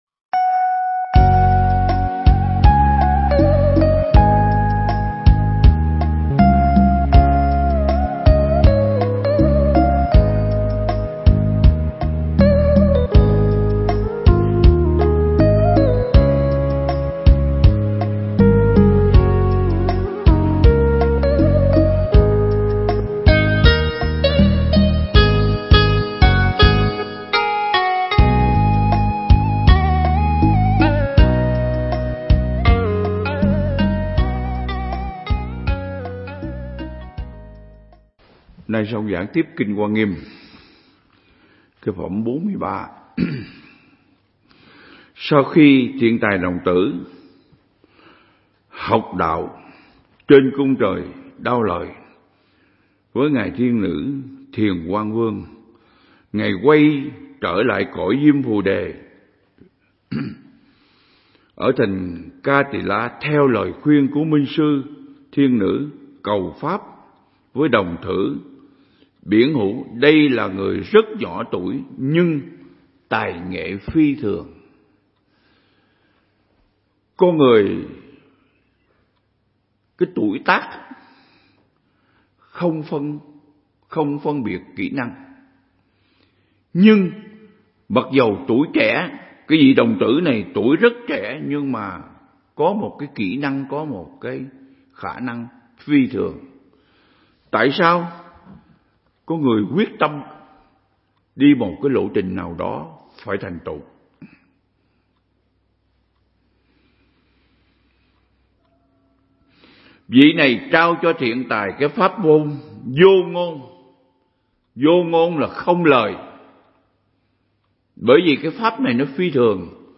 Mp3 Pháp Thoại Ứng Dụng Triết Lý Hoa Nghiêm Phần 61
giảng tại Viện Nghiên Cứu Và Ứng Dụng Buddha Yoga Việt Nam (TP Đà Lạt)